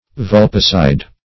Search Result for " vulpicide" : The Collaborative International Dictionary of English v.0.48: Vulpicide \Vul"pi*cide\, n. [L. vulpes a fox + caedere to kill.] One who kills a fox, except in hunting; also, the act of so killing a fox.